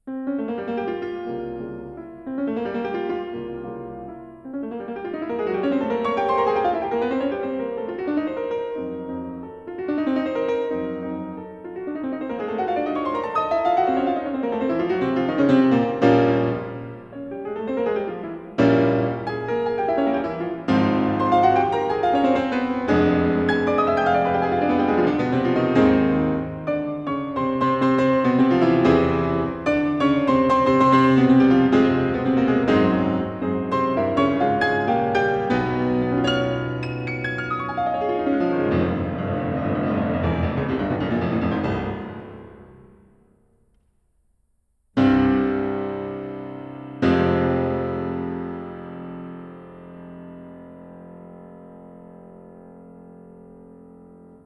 The analyzed Chopin performances were audio recordings played by 5 famous pianists:
Rafał Blechacz recorded in 2007